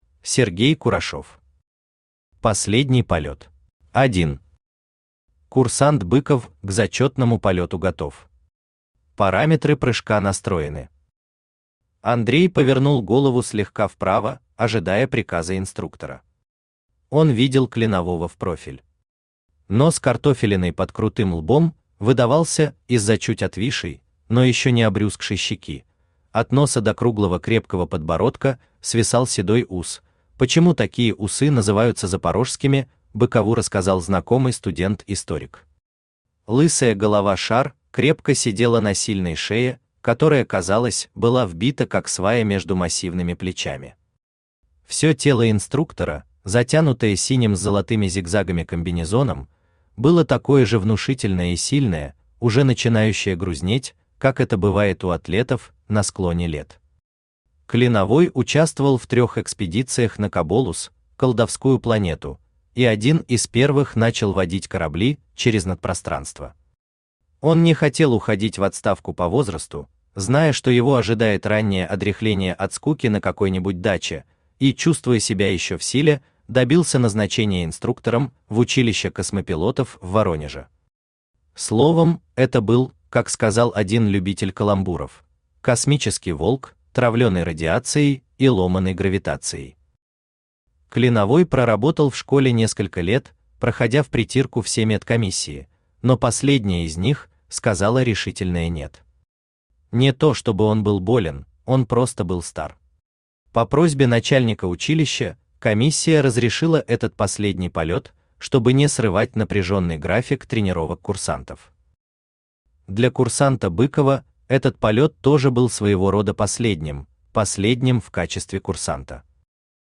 Аудиокнига Последний полёт | Библиотека аудиокниг
Читает аудиокнигу Авточтец ЛитРес.